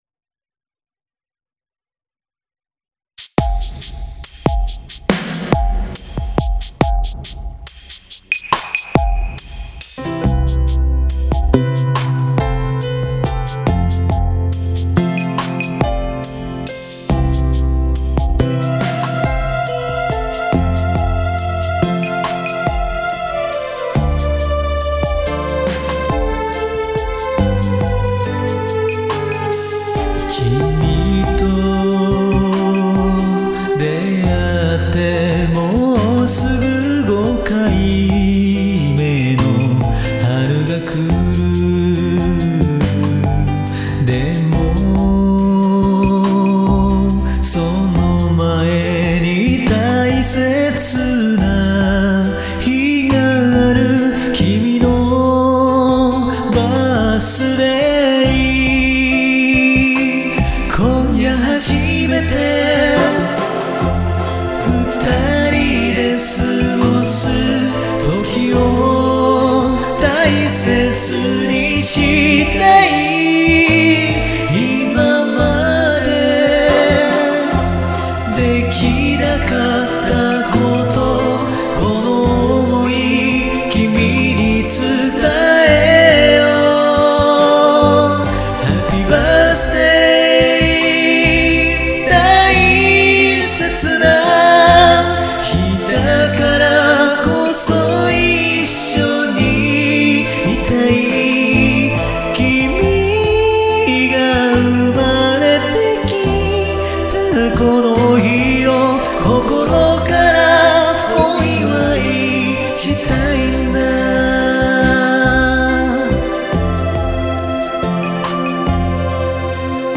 R＆Bバージョン
曲調は少しだけ幻想的な雰囲気で、モダン・ソウル風なアレンジになっています。
楽器構成は珍しくシンプルですが、仕上がりはいい出来だという感想をよく頂いています。